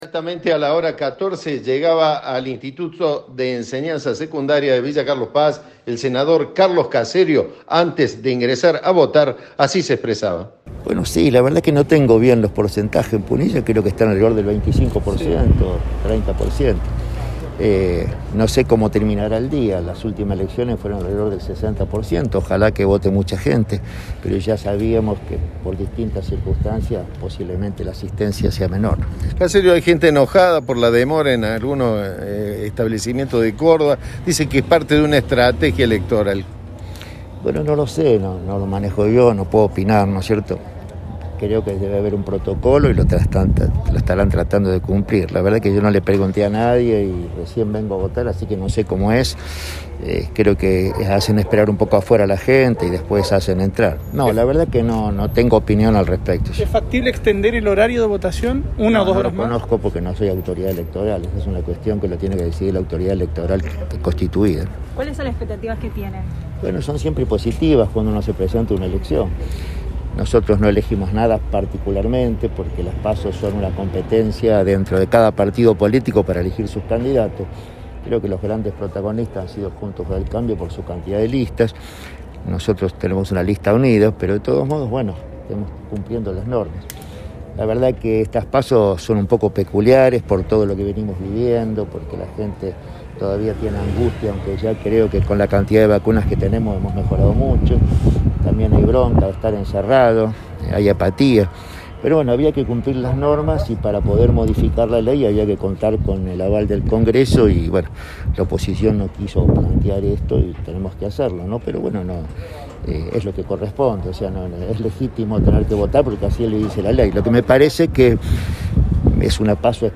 El precandidato a senador nacional de Córdoba por el Frente de Todos dijo Cadena 3 antes de votar que se mejoró mucho por la cantidad de vacunas pero que también hay "bronca de estar encerrados".
El precandidato a senador por Córdoba por el Frente de Todos, Carlos Caserio, votó este domingo en el instituto de Enseñanza Secundaria de Carlos Paz y consideró que se trata de elecciones "peculiares".